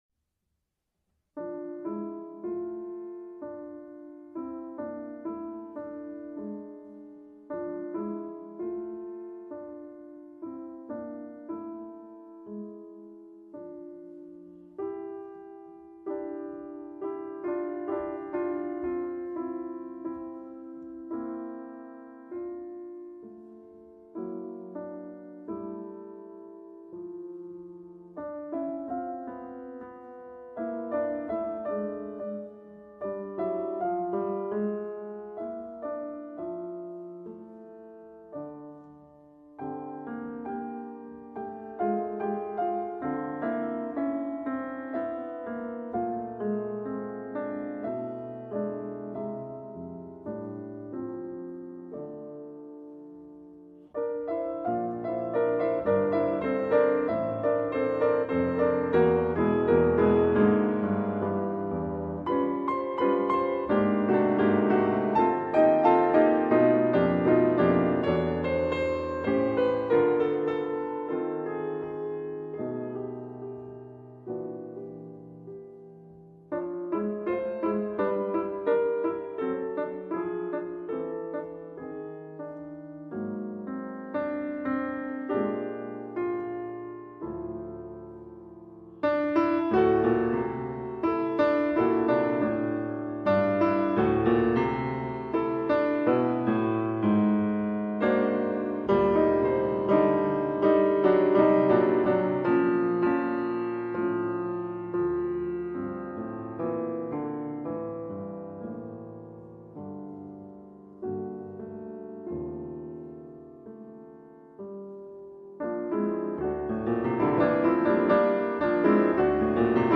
VIKTOR ULLMAN V. Variazioni